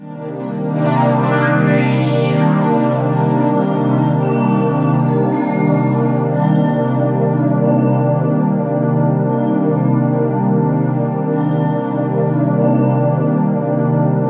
SWEEP04   -L.wav